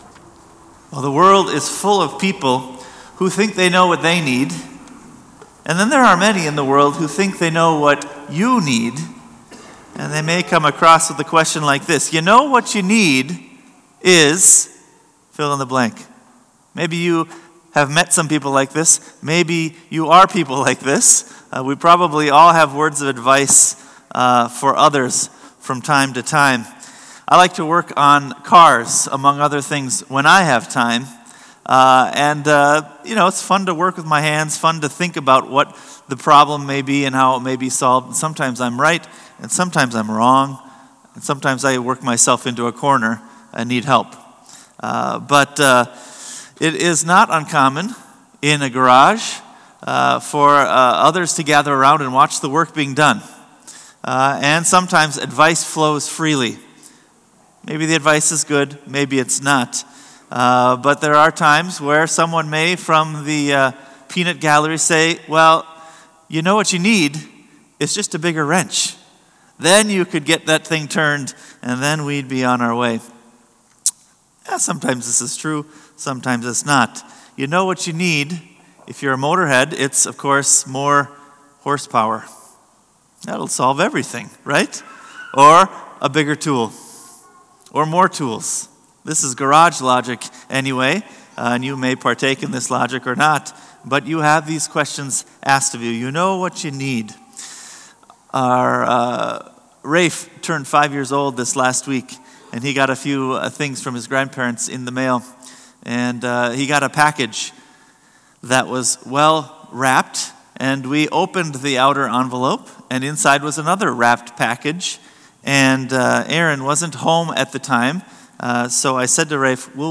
Sermon “Ya Know What’cha Need?”